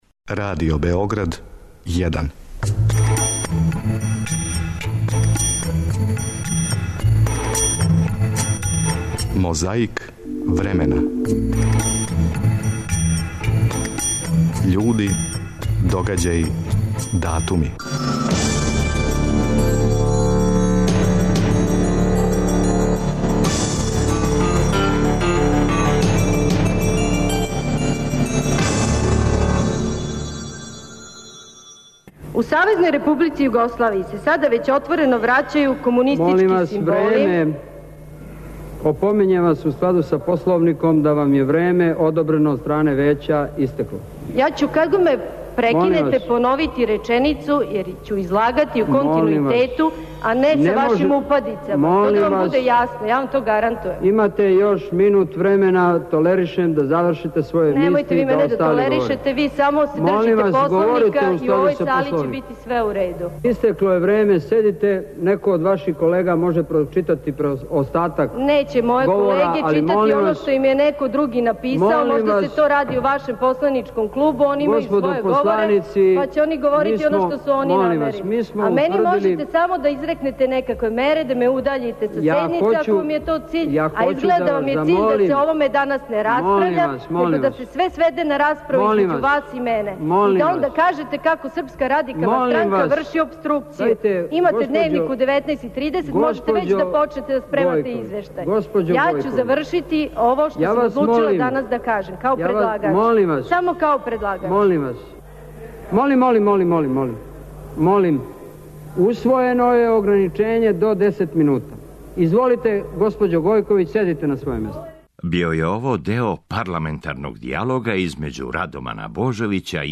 На почетку овонедељне борбе против пилећег памћења - део парламентарног дијалога између Радомана Божовића и Маје Гојковић, од 8. новембра 1994.
Пре 12 година Велимир Илић био је кандидат за председника Србије па је, тим поводом, 7. новембра 2003. гостовао у емисији Првог програма Радио Београда.
Подсећа на прошлост (културну, историјску, политичку, спортску и сваку другу) уз помоћ материјала из Тонског архива, Документације и библиотеке Радио Београда.